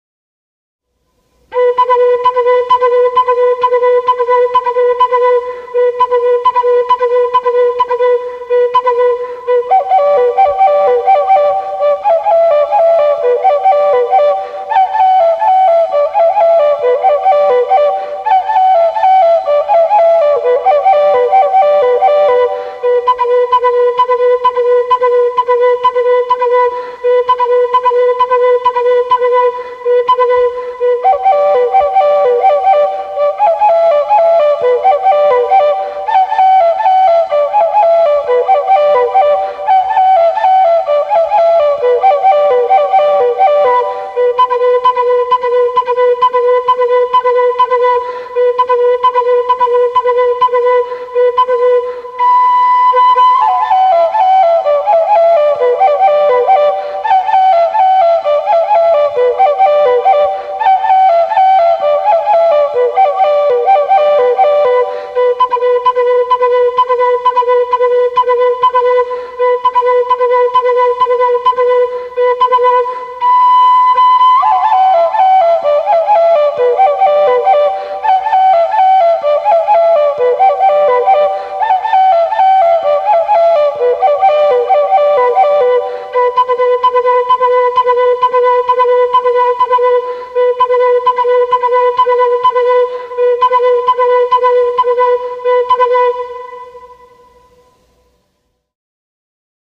unknow---crow-traditional-song.mp3